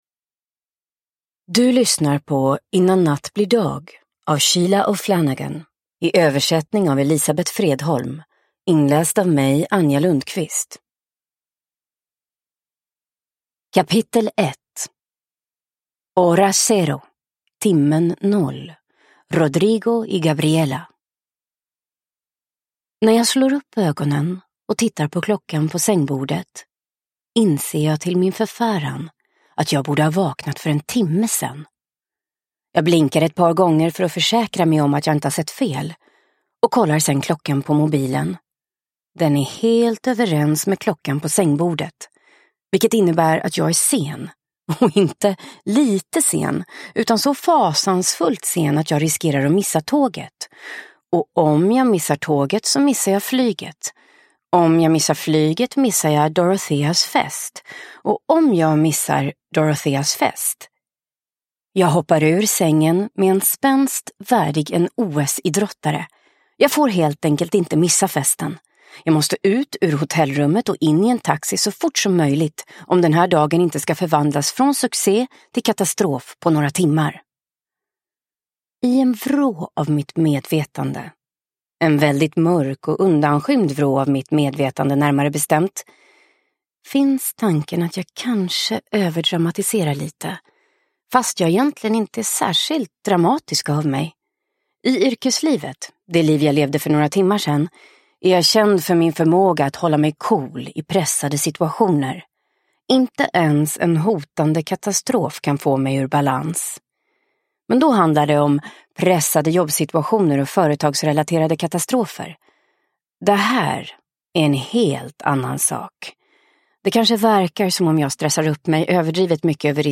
Innan natt blir dag – Ljudbok – Laddas ner